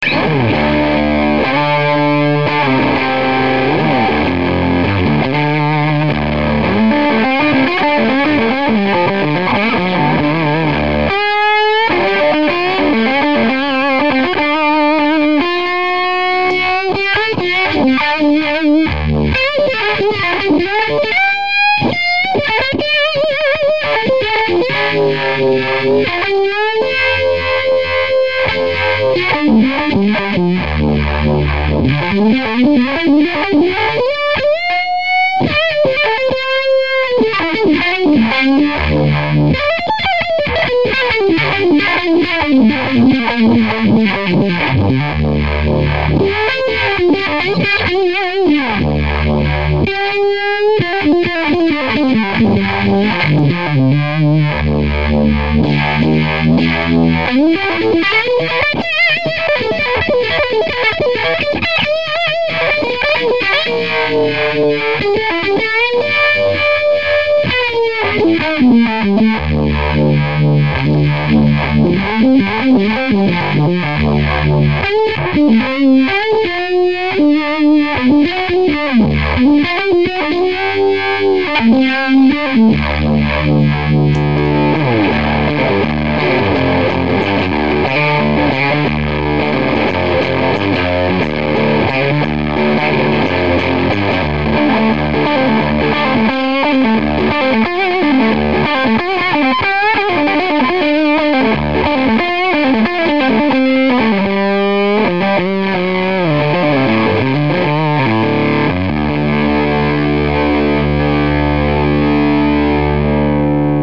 J'ai eu une london fuzz,une london fuzz II et celle ci sonne mieux,et est la pedale la plus silencieuse que j'ai jamais eue!Pas le moindre souffle meme a bloc!(contrairement aux MJM)
Voici 2 sample que j'ai fait a faible volume avec ma strat srv,mon rivera pubster45 et ma mjm sixties vibe sur le 2eme,repris par un sm57 branché ds la carte son du pc(ca sonne mieux a fort volume j'ai pas encore eu le temps d'en faire d'autres mais ca sonne quand meme ):
FUZZFace
Rivera+fuzzfaceDropD+vibe.mp3